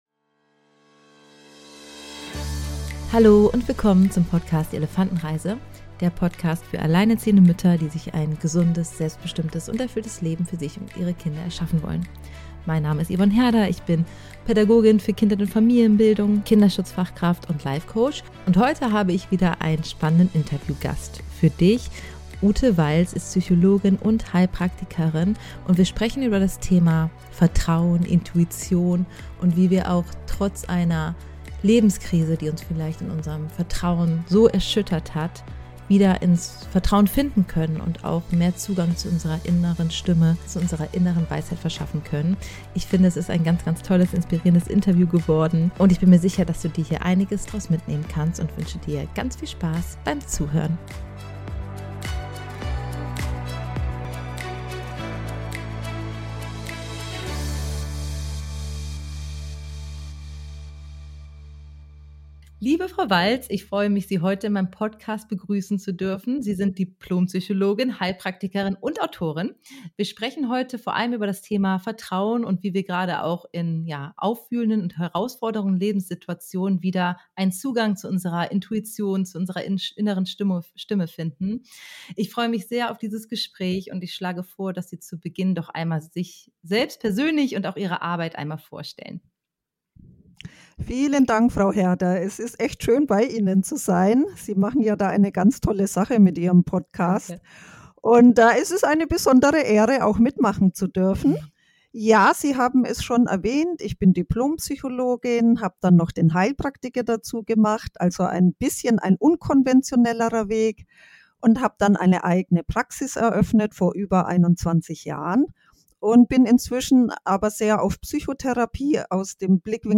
Vertrauen, wenn das Vertrauen gebrochen ist - Interview mit Dipl.